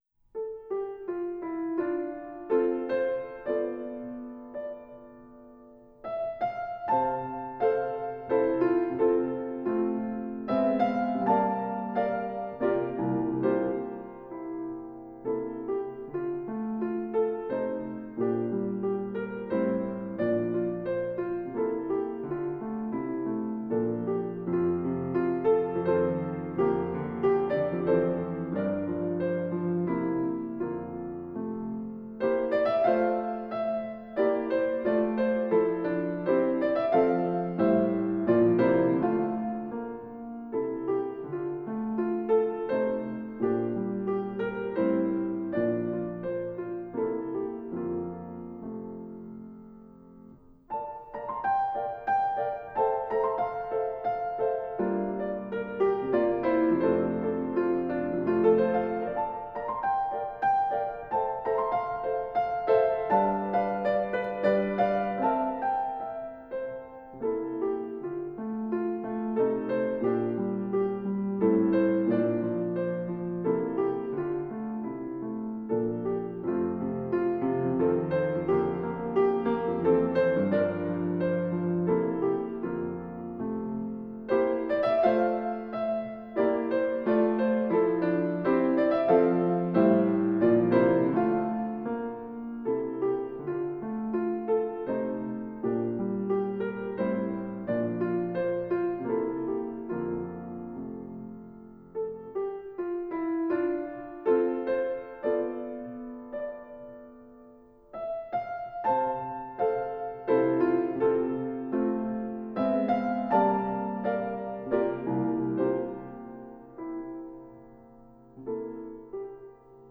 SOLO BRASS
B♭ Accompaniment Track